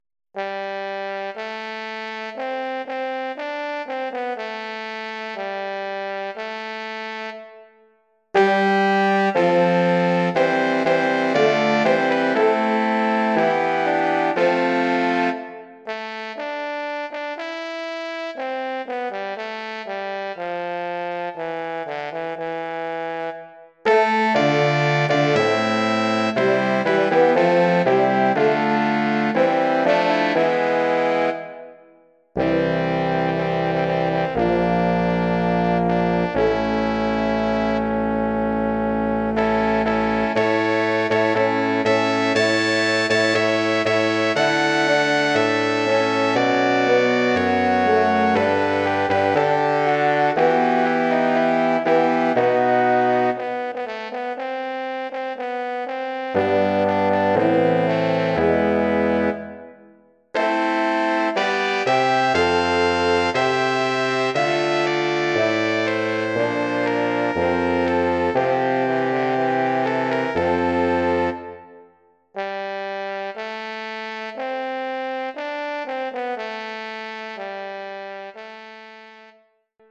Bearbeitung für Blechbläserquintett
Besetzung: 2 Trompeten, Horn, Posaune, Tuba
arrangement for brass quintet
Instrumentation: 2 trumpets, horn, trombone, tuba